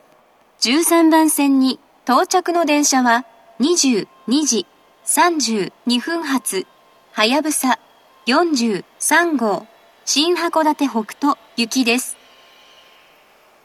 １３番線到着放送
接近放送及び到着放送は「はやぶさ４３号　新函館北斗行」です。